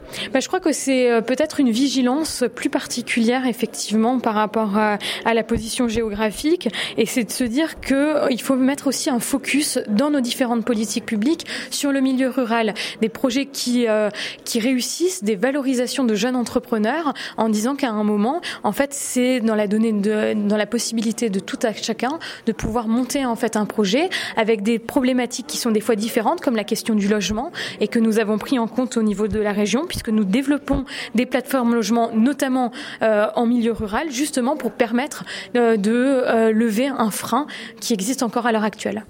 C’est le mot d’ordre du deuxième défi présentait par la Région pendant son passage à Verdun, lundi 26 février.
Comment faire entreprendre dans les milieux ruraux où il y a moins de moyens. Elsa Schalck donne la vision de la Région sur ce sujet.